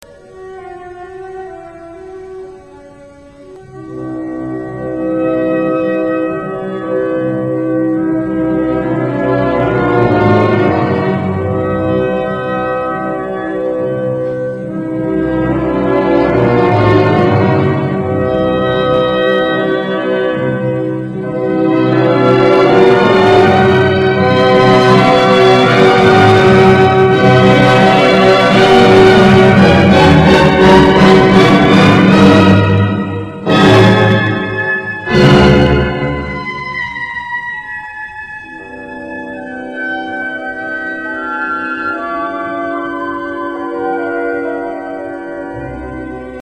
(Ausschnitt aus dem Adagio)